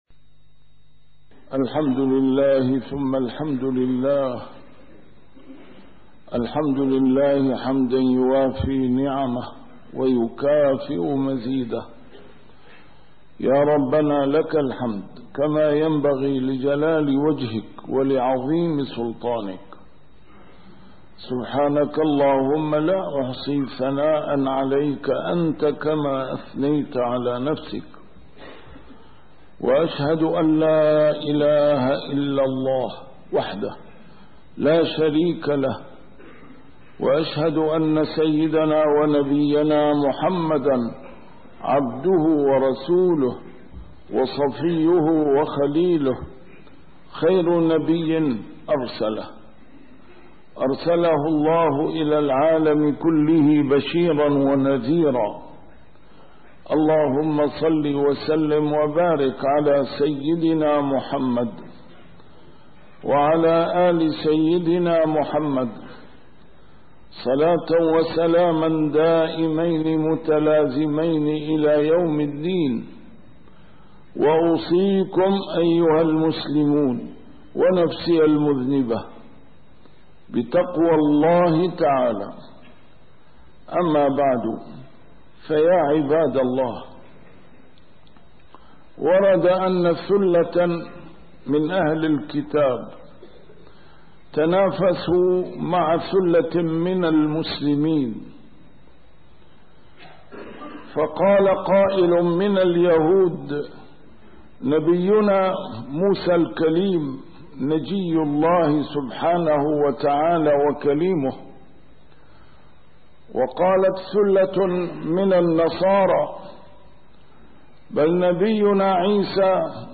A MARTYR SCHOLAR: IMAM MUHAMMAD SAEED RAMADAN AL-BOUTI - الخطب - من يعمل سوءاً يجز به